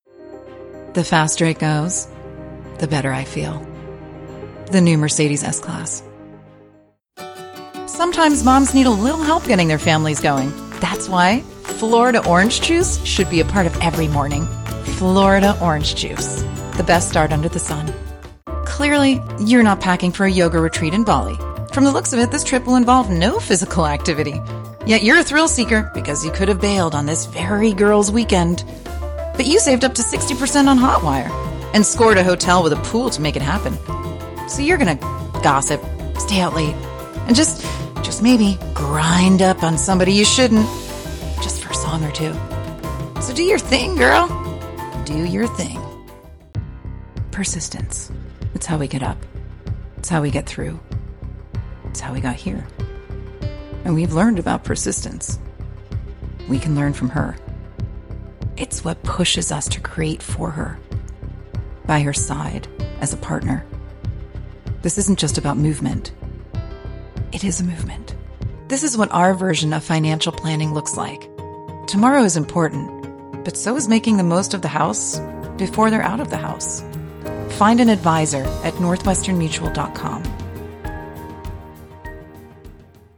PROFESSIONAL HOME STUDIO:
Neumann TLM 103
The Sophisticated, International, Clear and Trustworthy Voice You’re Looking For
COMMERCIAL
CommerciallDemo_1.5min-1.mp3